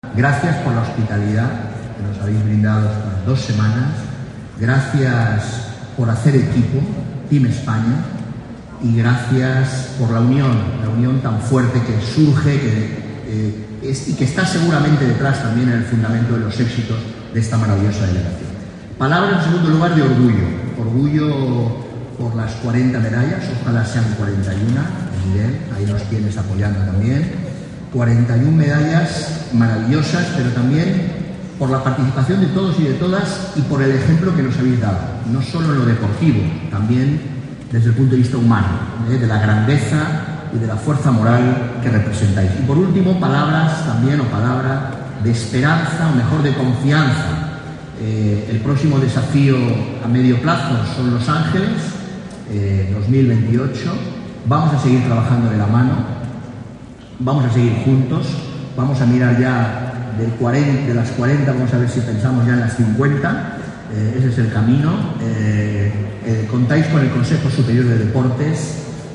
Foto de familia del acto de acogida en Madrid del equipo paralímpicoLa ministra de Educación, Formación Profesional y Deportes, Pilar Alegría, junto al ministro de Derechos Sociales, Consumo y Agenda 2030, Pablo Bustinduy, homenajearon el 10 de seprtiembre en Madrid al Equipo Paralímpico Español, tras el éxito logrado en los Juegos de París 2024 en los que ha logrado un total de 40 medallas.